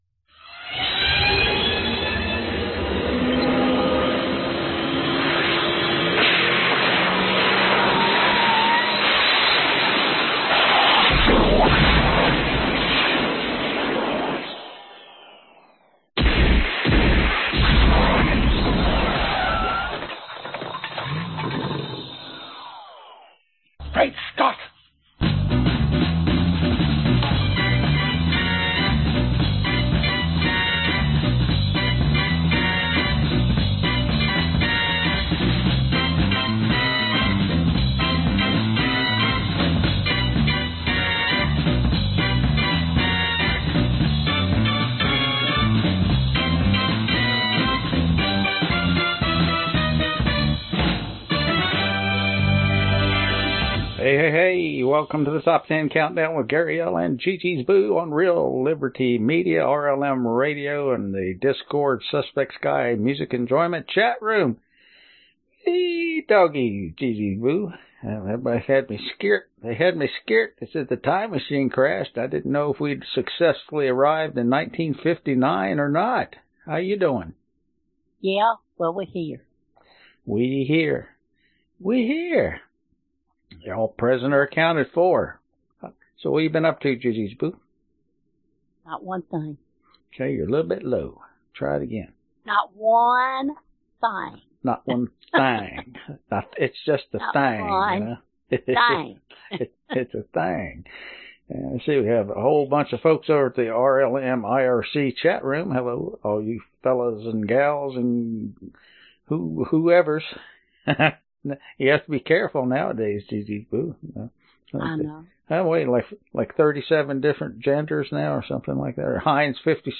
1957 Genre Oldies Year 2021 Duration 1:01:46